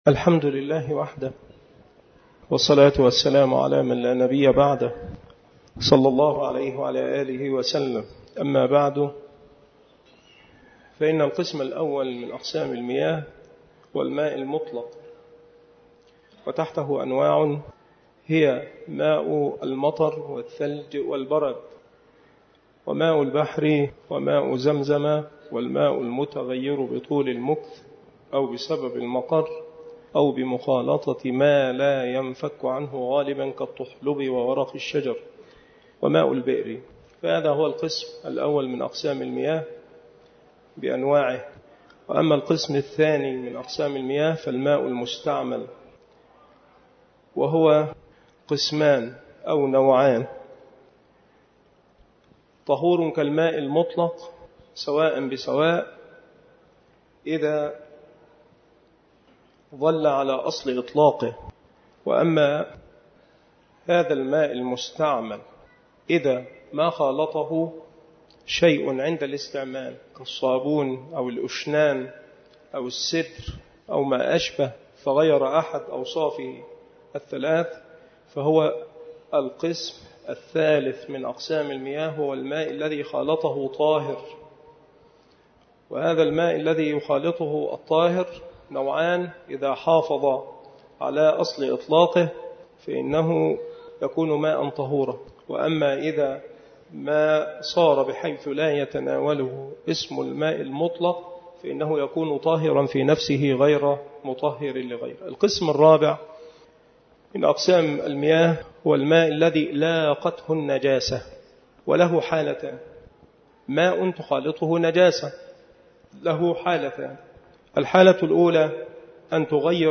مكان إلقاء هذه المحاضرة بالمسجد الشرقي بسبك الأحد - أشمون - محافظة المنوفية - مصر عناصر المحاضرة : القسم الرابع : الماء الذي لاقته النجاسة.